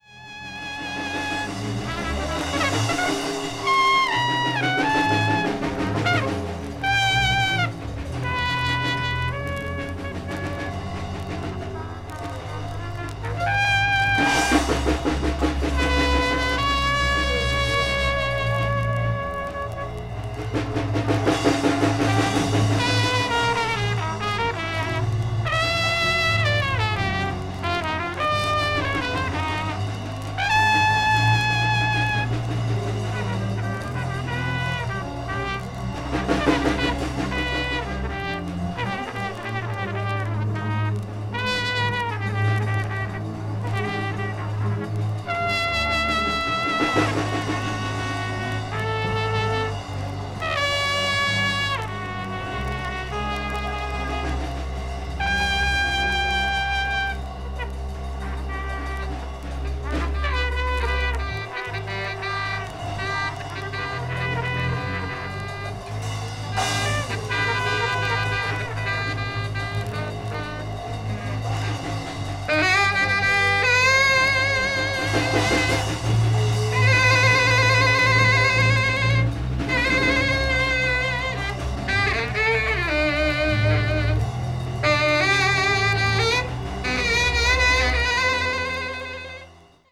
engaging in intense, lightning-fast interplay.
avant-jazz   free improvisation   free jazz   spiritual jazz